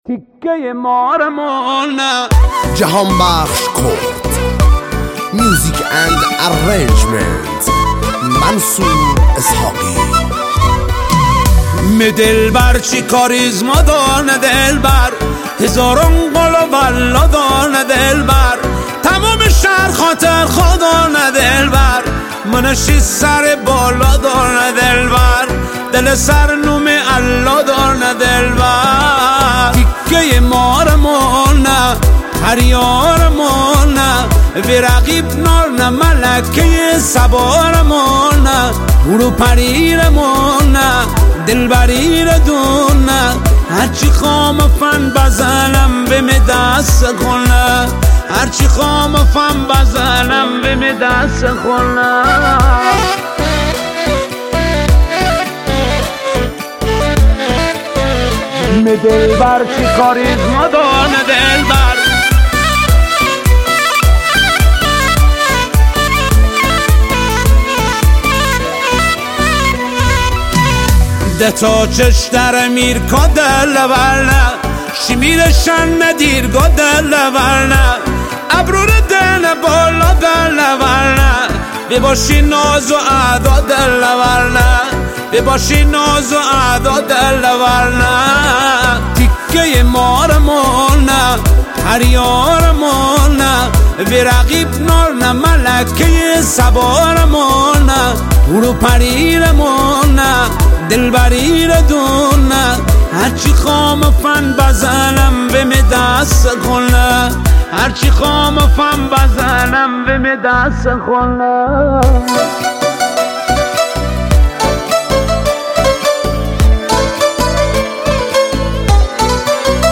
ترانه زیبا جدید مازندرانی